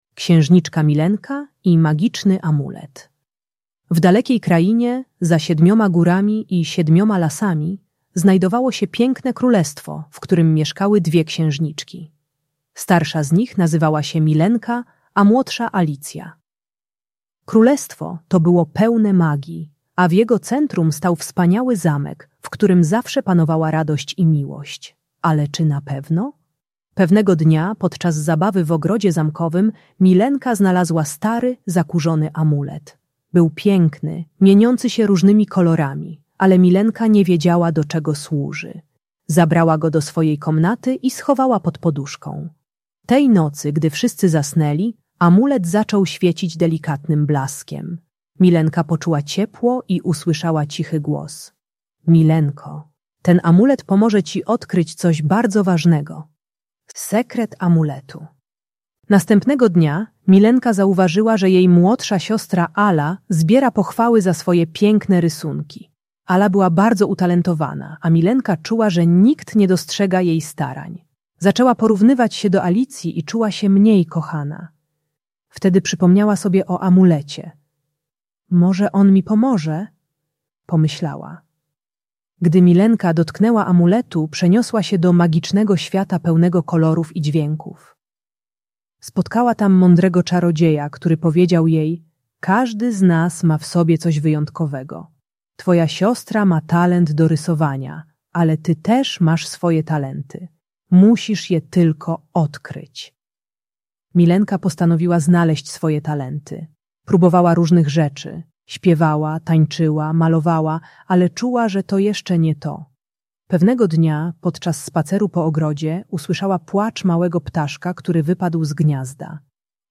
Pomaga przedszkolakom i młodszym dzieciom szkolnym zrozumieć, że rodzice kochają każde dziecko na swój sposób. Uczy techniki odkrywania własnych talentów zamiast porównywania się z bratem czy siostrą. Audiobajka o zazdrości między rodzeństwem.